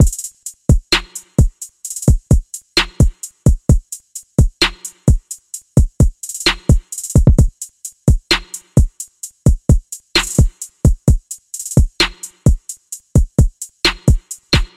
描述：踢鼓和嗵鼓是经过修改的Risset鼓样本。 我制作小鼓的方法是将普通的小鼓采样，反转，加入混响，然后再反转一次。
Tag: 120 bpm Chill Out Loops Drum Loops 1.35 MB wav Key : Unknown